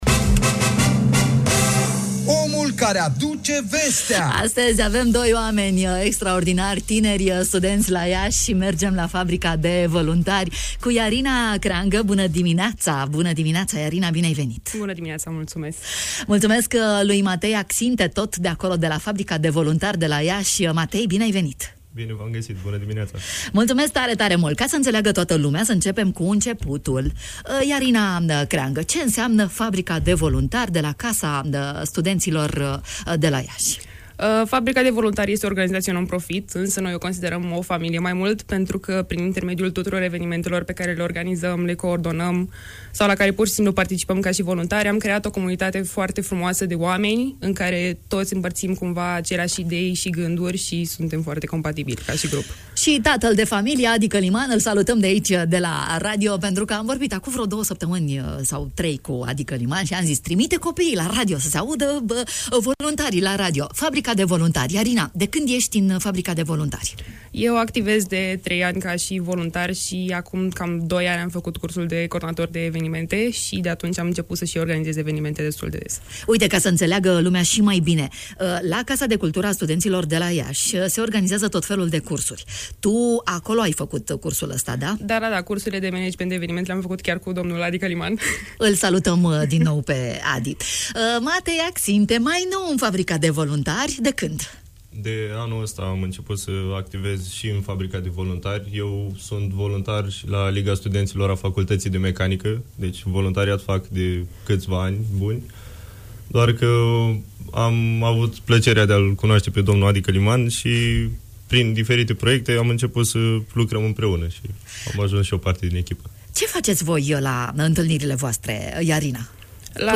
Fabrica de Voluntari la Radio România Iași.